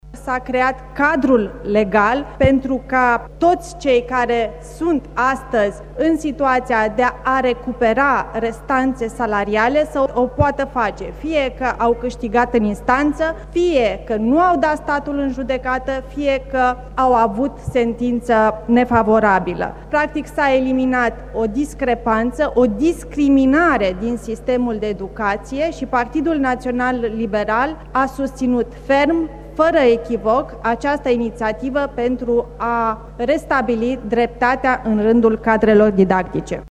Membru al Comisiei pentru învăţământ, deputatul liberal Raluca Turcan a explicat că plata diferenţelor salariale cuvenite profesorilor nu va mai fi condiţionată de obţinerea unei hotărâri judecătoreşti favorabile: